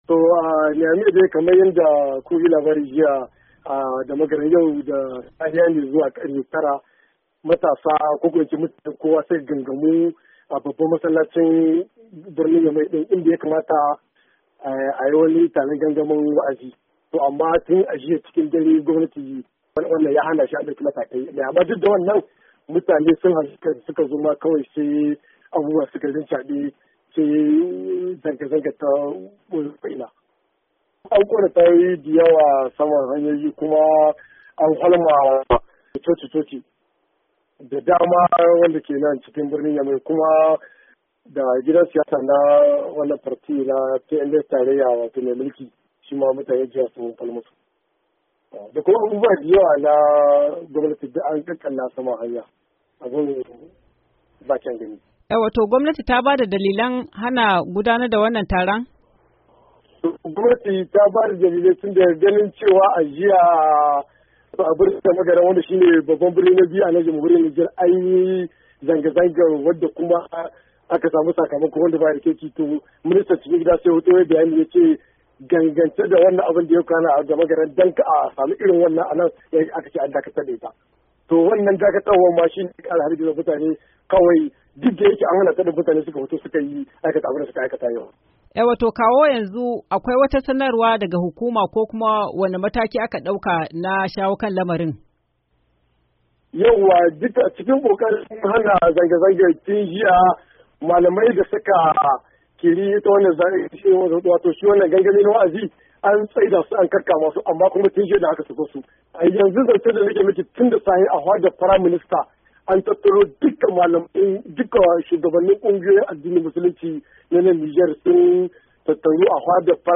Rahoton Zanga Zanga a Miamey